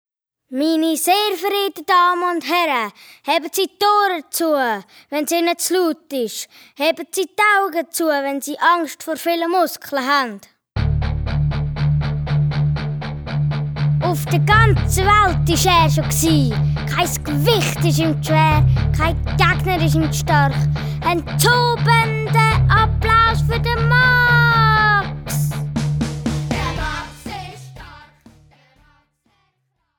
Besetzung: Gesang